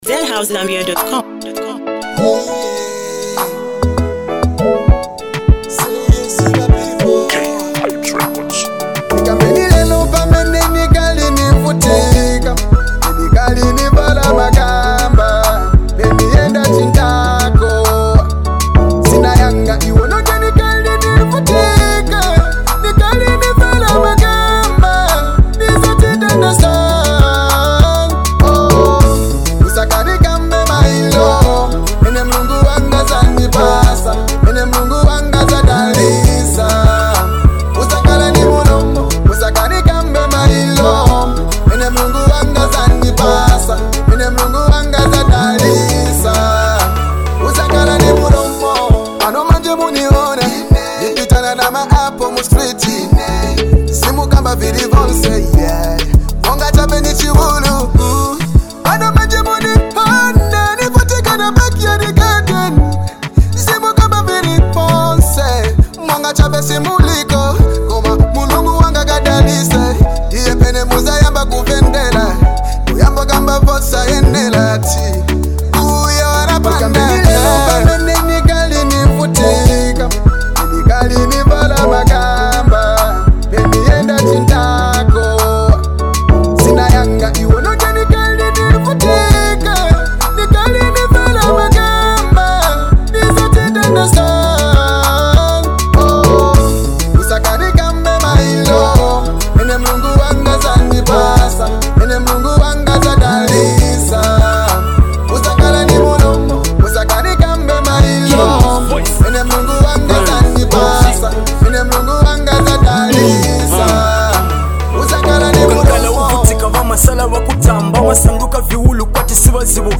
heartfelt anthem